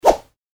戦闘 （163件）
振り回す1.mp3